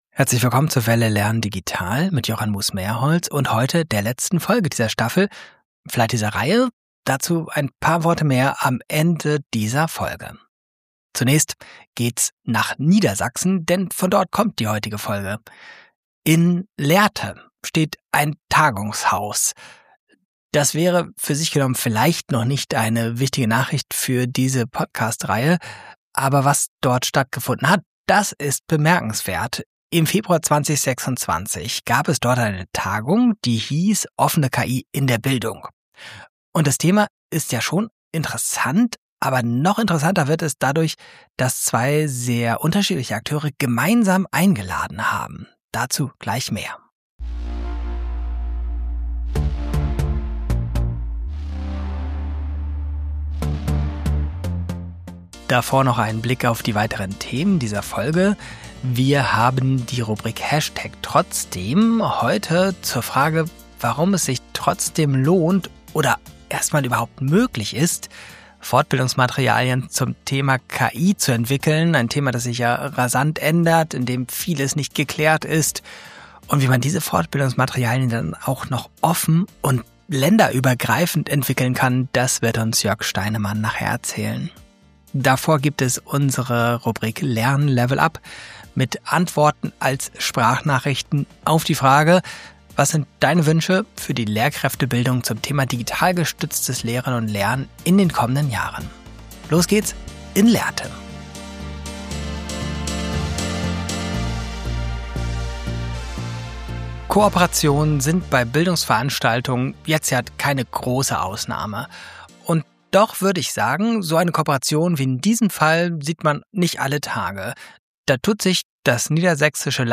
Die Sprachnachrichten aus der Praxis widmen sich den Wünschen für die Lehrkräftebildung der Zukunft.